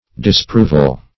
Disproval \Dis*prov"al\, n. Act of disproving; disproof.